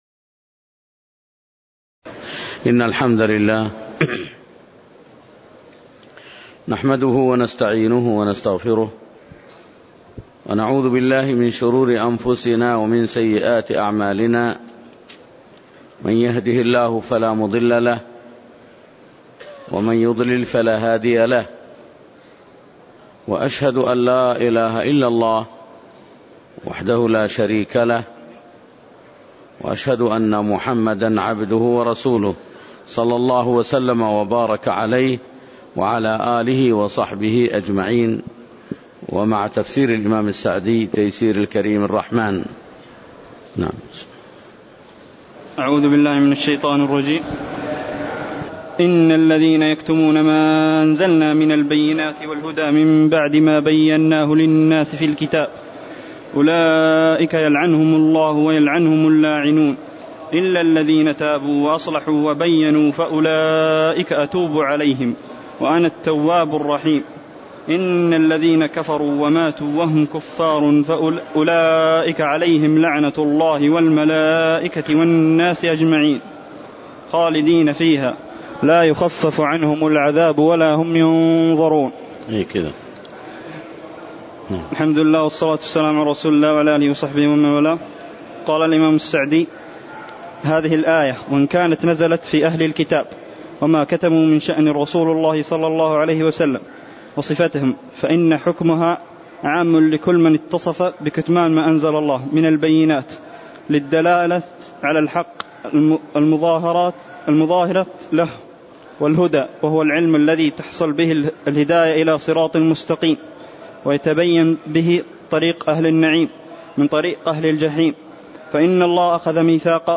تاريخ النشر ٢٥ رجب ١٤٣٨ هـ المكان: المسجد النبوي الشيخ